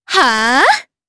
Shamilla-Vox_Attack4_jp.wav